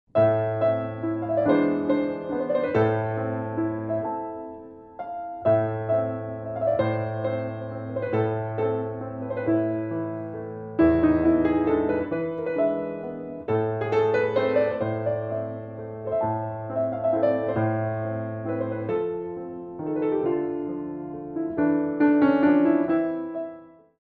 Ballet Music for All Level Classes
Solo Piano
Moderate Waltzes